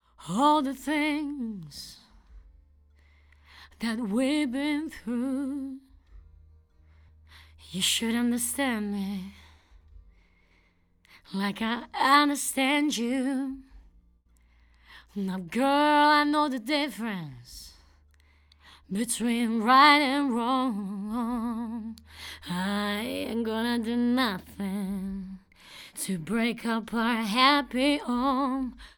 NEUMANN-M149.mp3